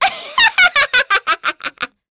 Pansirone-Vox_Casting4_kr.wav